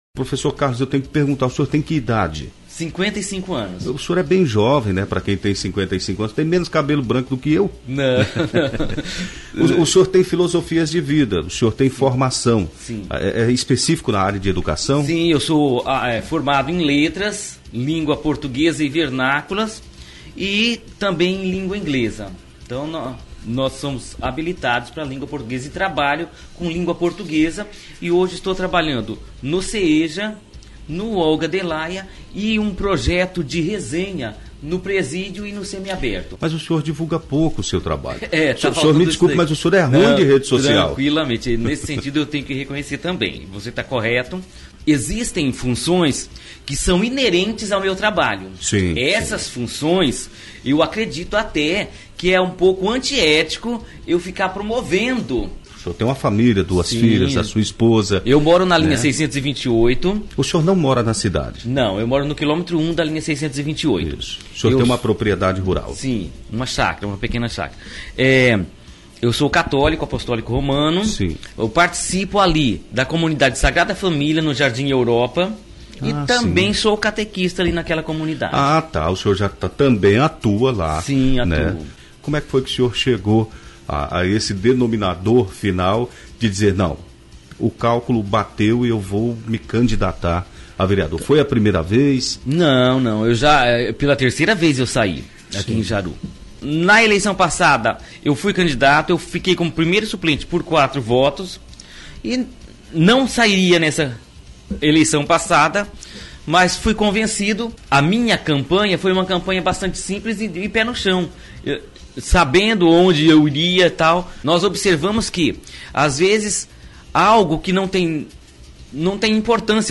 Vereador Professor Carlos, durante entrevista na Massa FM Jaru.
ENTREVISTA-MASSA-FM-JARU.mp3